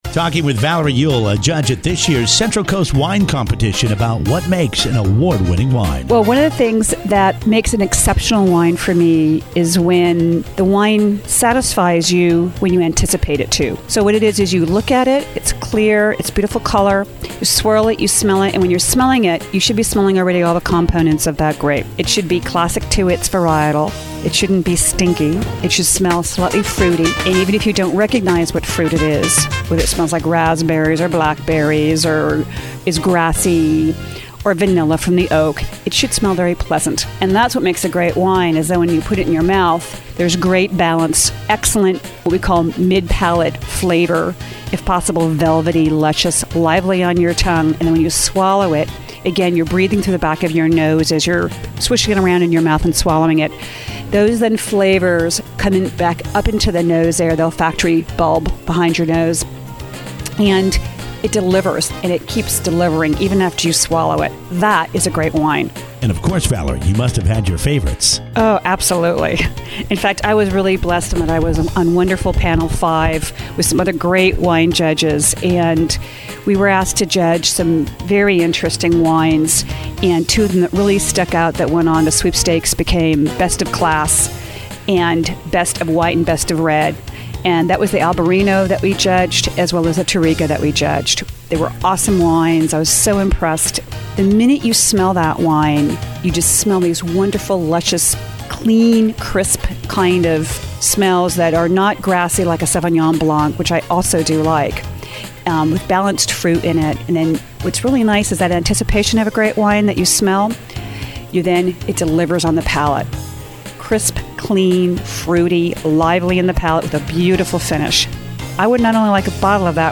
Live Interviews with CCWC Judges and the KRUSH Radio